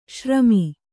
♪ śrami